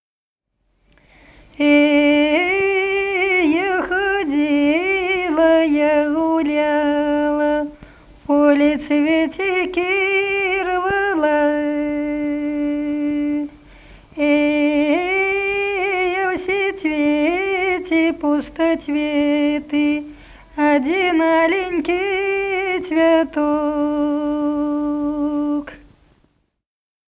Народные песни Смоленской области
«Эй, я ходила, я гуляла» (лирическая).